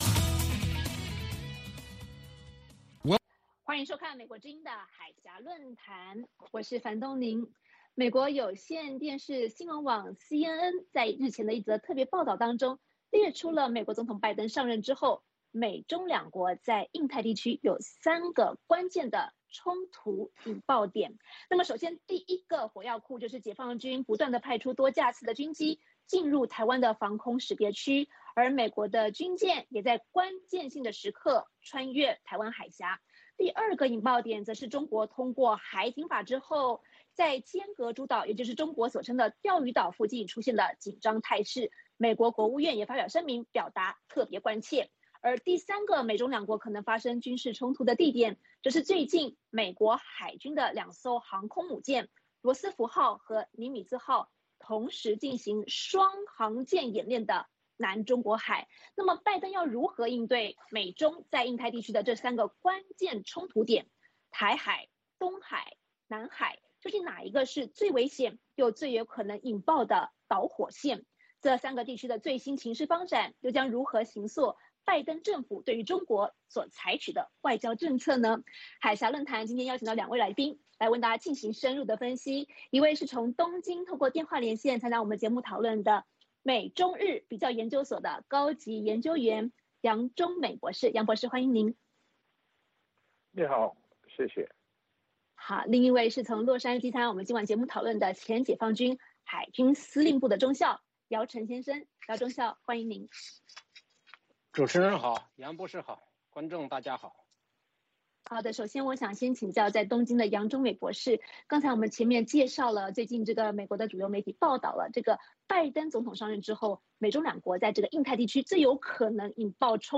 美国之音中文广播于北京时间每周日晚上9点播出《海峡论谈》节目(电视、广播同步播出)。《海峡论谈》节目邀请华盛顿和台北专家学者现场讨论政治、经济等各种两岸最新热门话题。